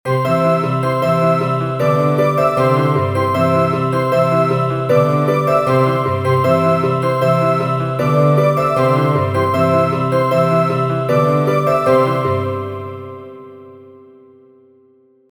接近メロディー(普通).mp3